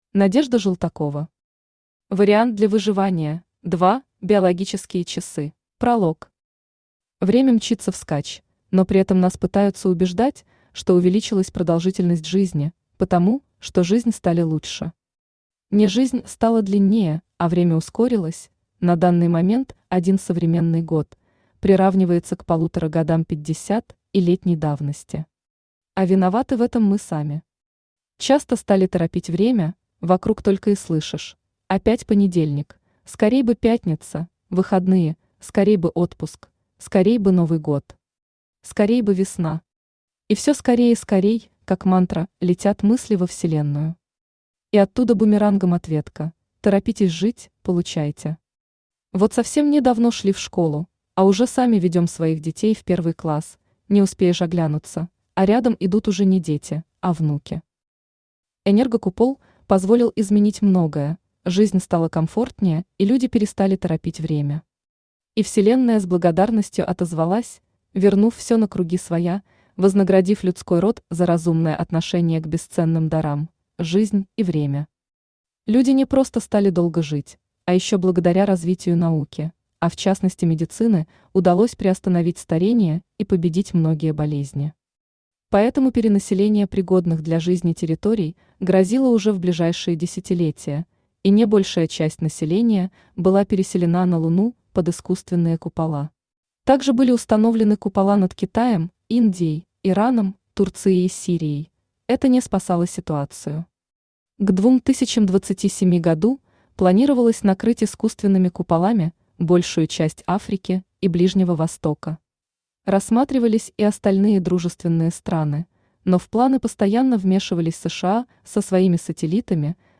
Аудиокнига Вариант для выживания – 2 (Биологические часы) | Библиотека аудиокниг
Aудиокнига Вариант для выживания – 2 (Биологические часы) Автор Надежда Желтакова Читает аудиокнигу Авточтец ЛитРес.